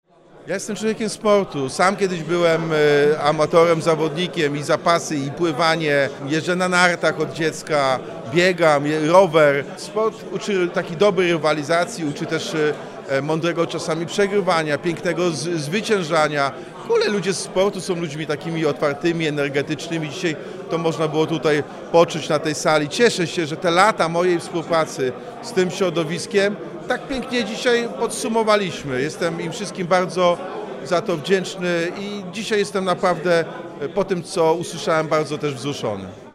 Jak poparcie sportowców ocenia Jacek Sutryk? – To dla mnie bardzo ważny głos. – mówi włodarz Wrocławia.